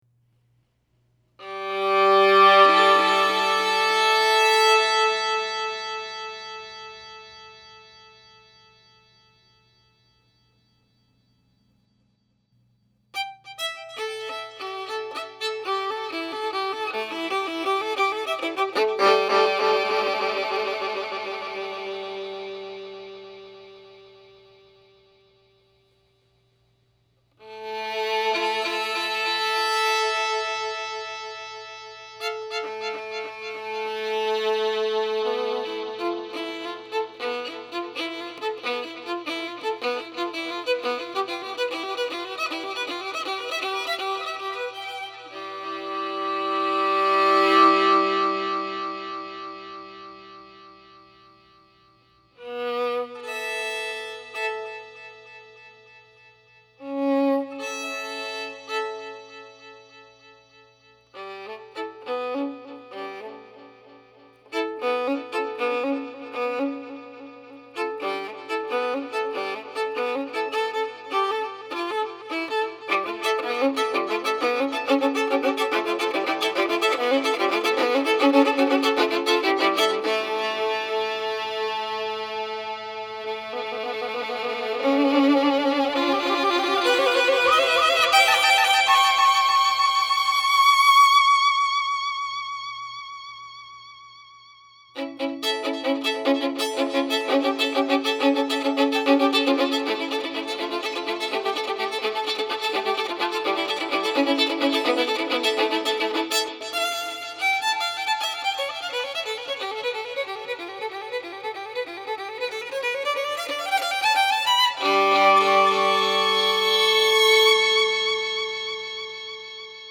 for Solo Violin and Live Electronics 6:00 Conganess